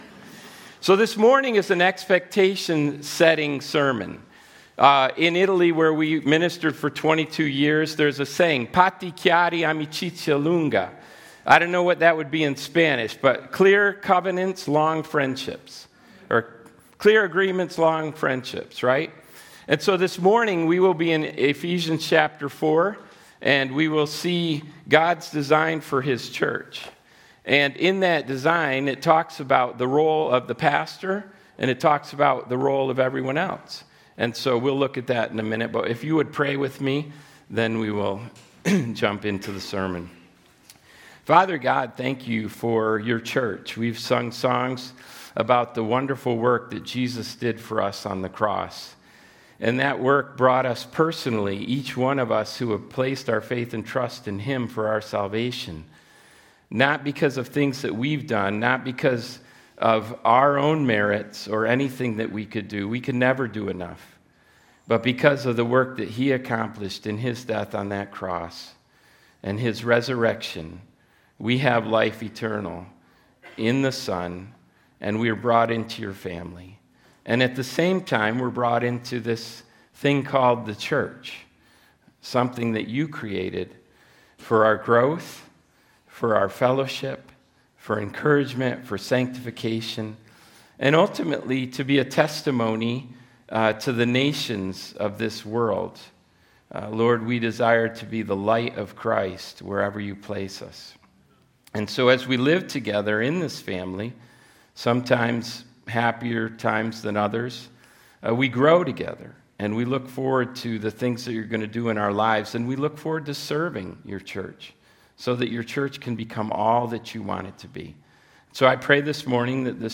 A message from the series "Sunday Service."